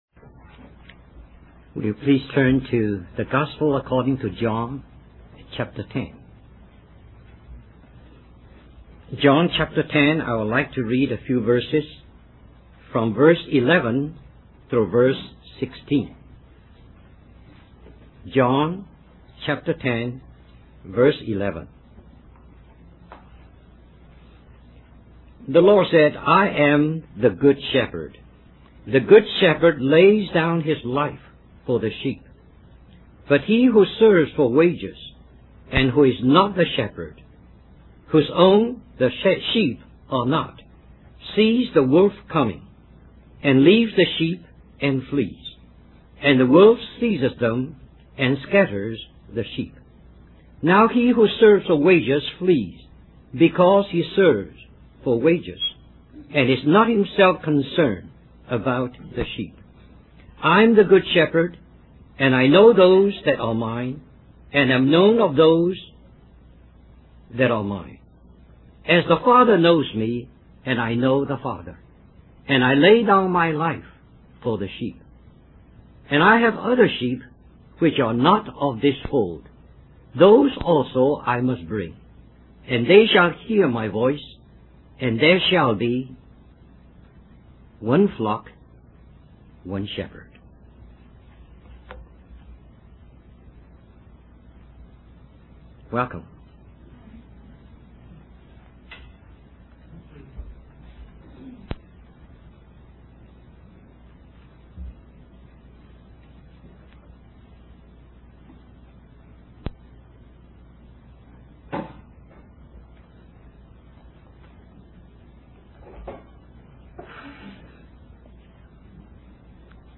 Florida Leadership Conference